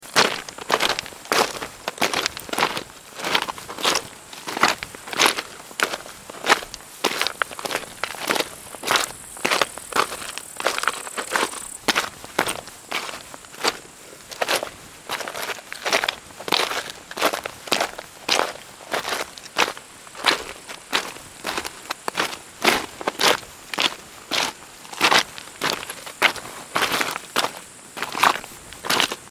دانلود آهنگ کوه 6 از افکت صوتی طبیعت و محیط
دانلود صدای کوه 6 از ساعد نیوز با لینک مستقیم و کیفیت بالا
جلوه های صوتی